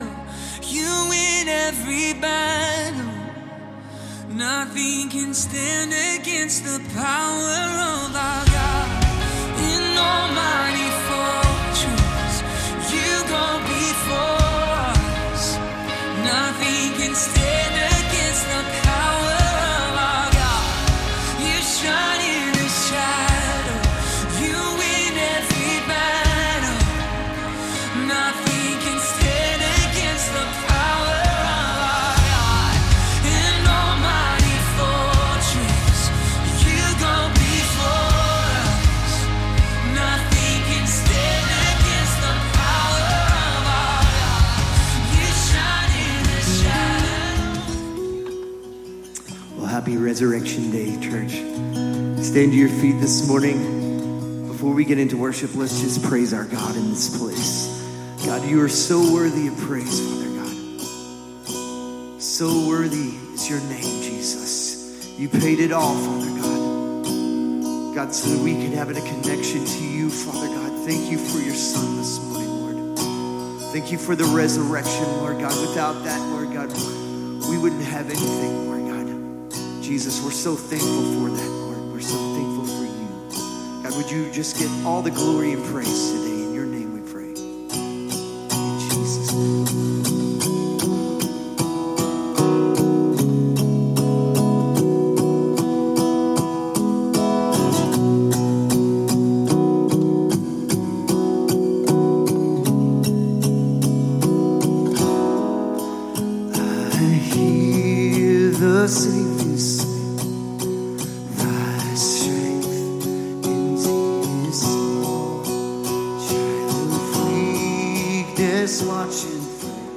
Calvary Knoxville Sunday AM Live!